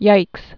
(yīks)